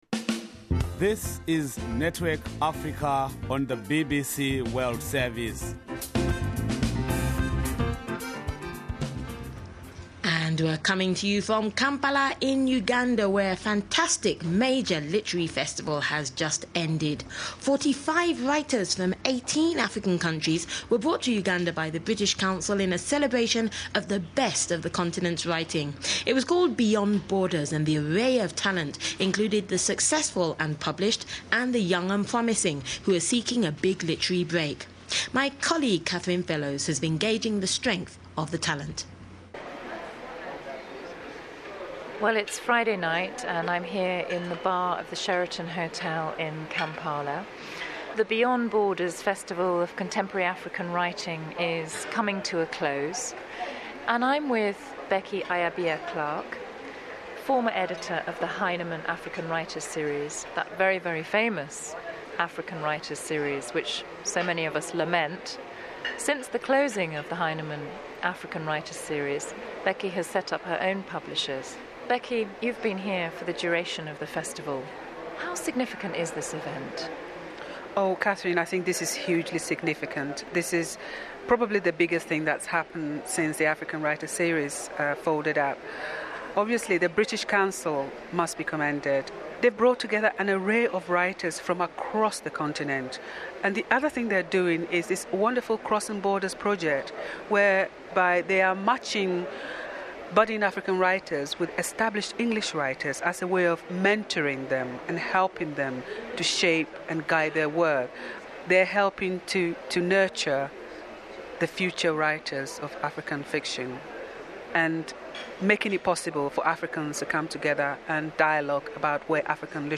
BBC World Service Interviews at the Beyond Borders Festival
The following interviews were broadcast on BBC Network Africa at the Weekend on BBC World Service to audiences across the African continent between October 2005 and March 2006.